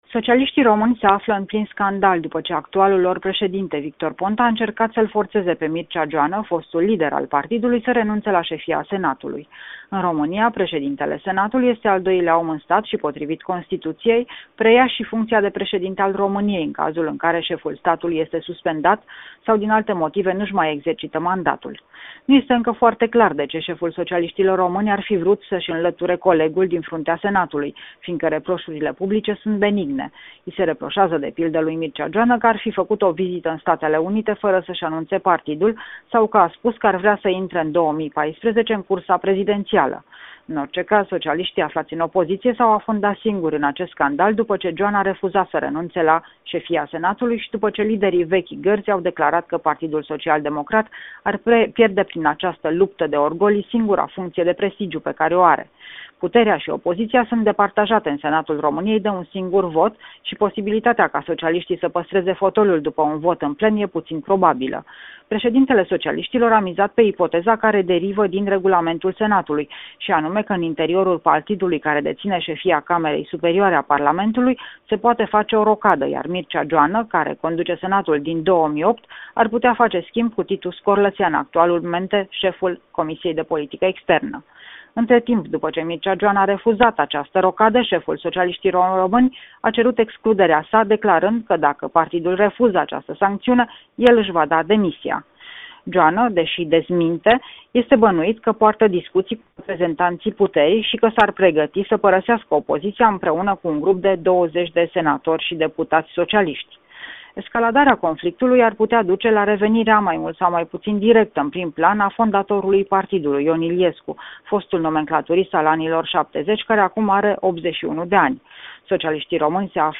Corespondenţa zilei de la Bucureşti